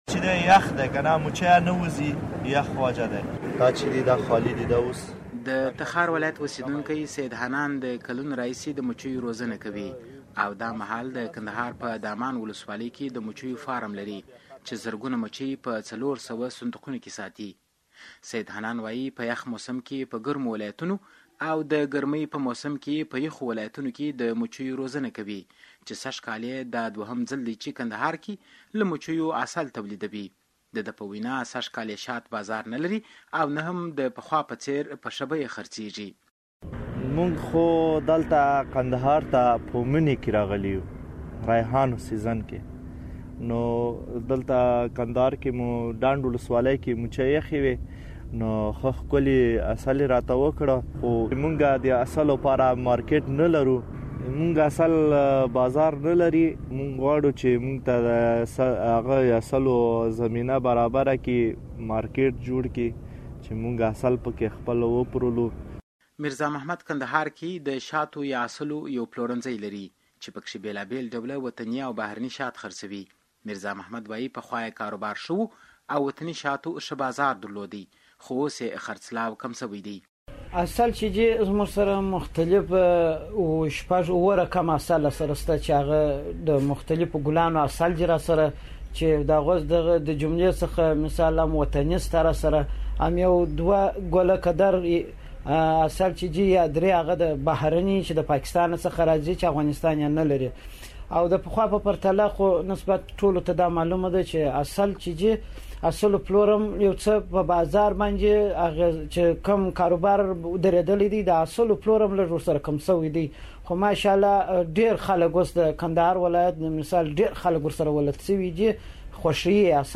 د کندهار د شاتو مچیو راپور